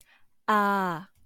Long and short vowel sounds
Short A (audio/mpeg)